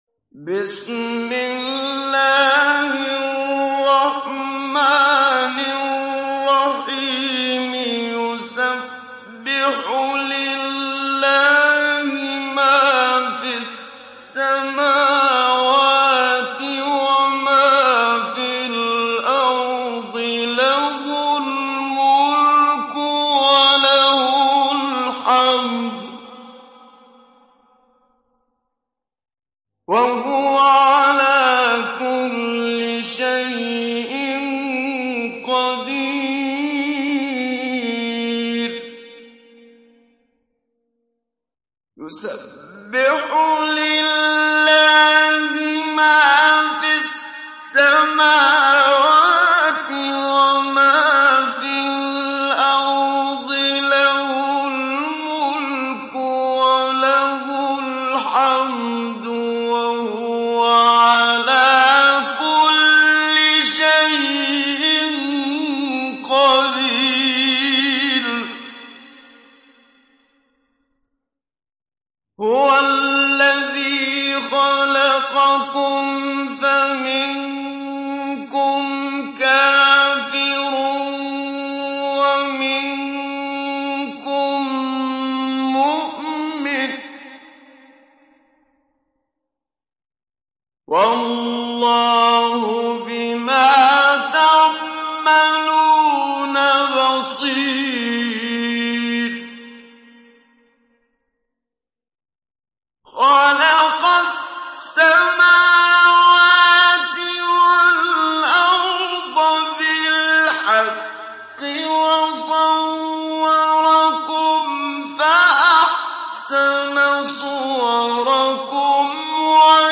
সূরা আত-তাগাবুন ডাউনলোড mp3 Muhammad Siddiq Minshawi Mujawwad উপন্যাস Hafs থেকে Asim, ডাউনলোড করুন এবং কুরআন শুনুন mp3 সম্পূর্ণ সরাসরি লিঙ্ক
ডাউনলোড সূরা আত-তাগাবুন Muhammad Siddiq Minshawi Mujawwad